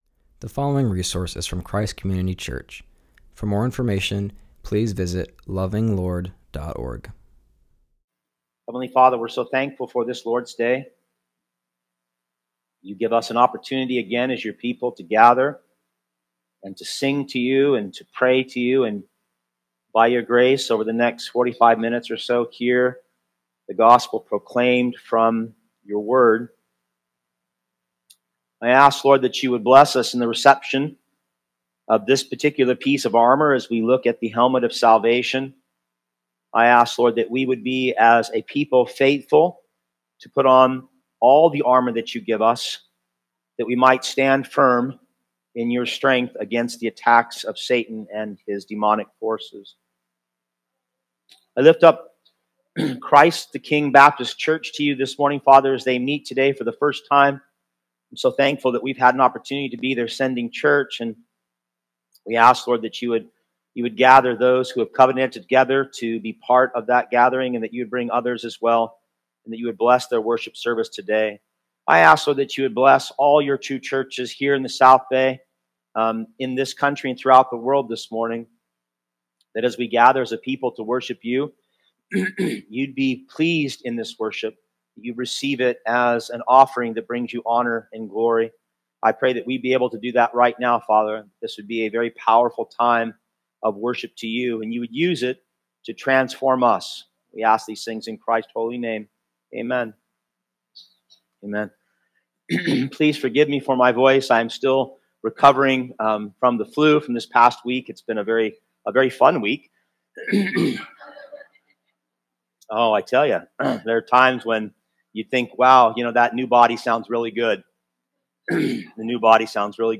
continues our series and preaches from Ephesians 6:17.